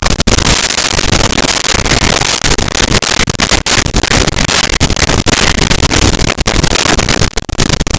Bite Chew Crunch Gulp
Bite Chew Crunch Gulp.wav